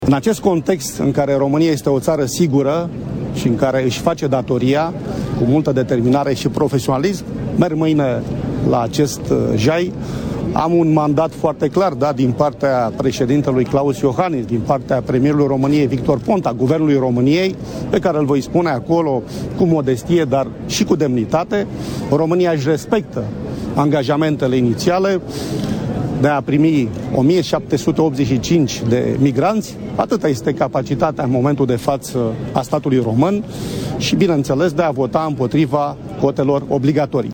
Declaratia a fost facuta dupa ceremonia dedicata Zilei Pompierilor. Vicepremierul a precizat ca Romania este o tara sigura, care isi face datoria atunci cand vine vorba despre apararea granitelor Uniunii Europene.